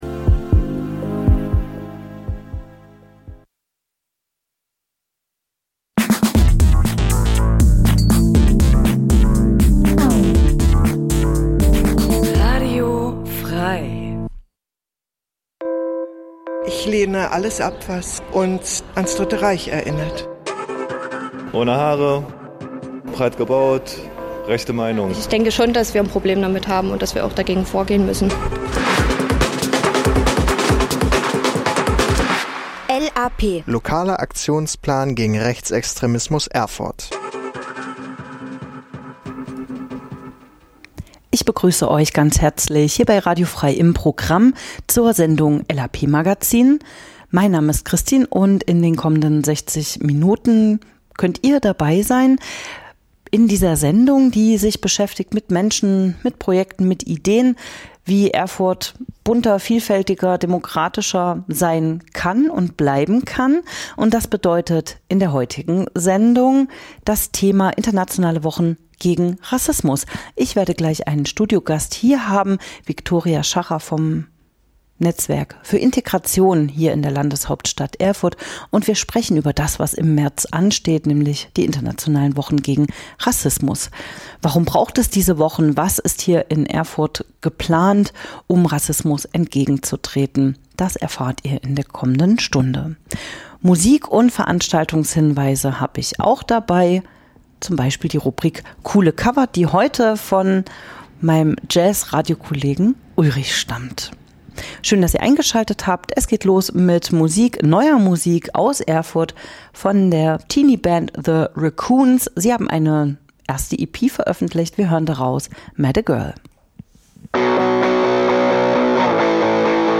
In der Sendung h�rt ihr verschiedene Beitr�ge rund um Demokratie und gegen Rechts, z. B. - Interviews zu aktuellen Themen
Musikrubrik "Coole Cover"